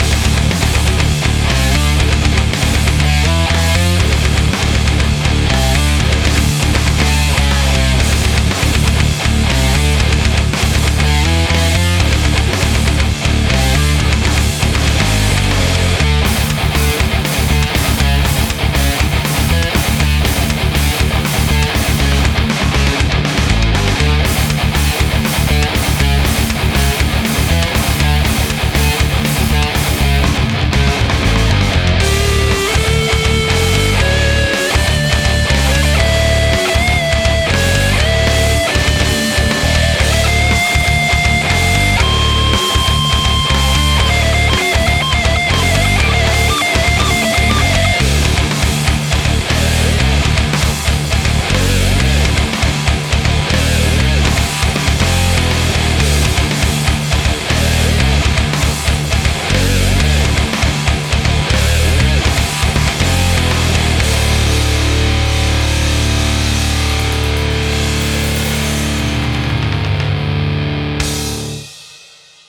Test metal sound